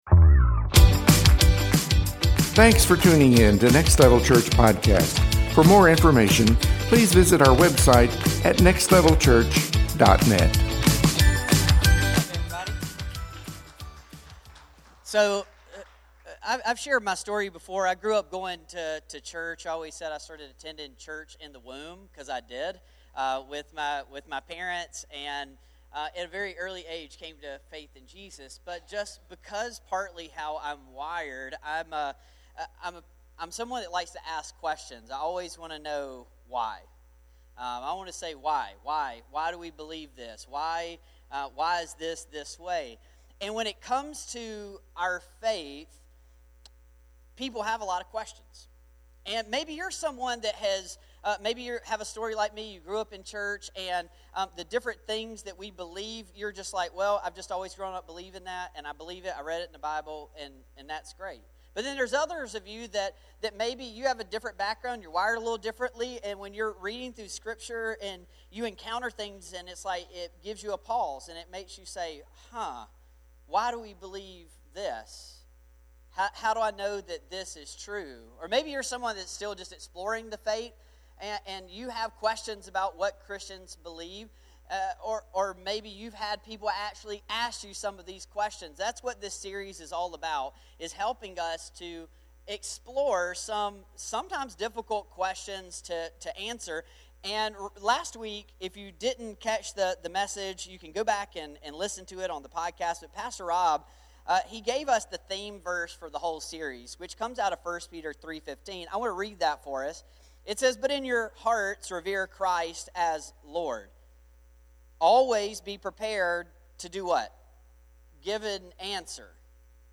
Questions for Christians Service Type: Sunday Morning Watch « Questions for Christians